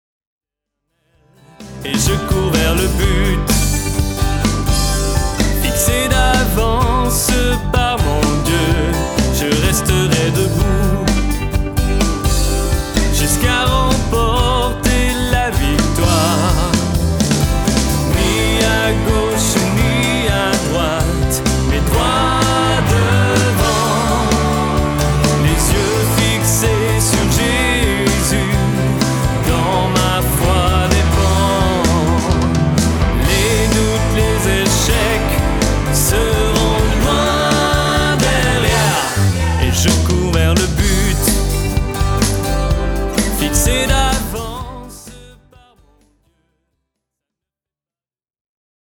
Chants de louange originaux interprétés par leurs auteurs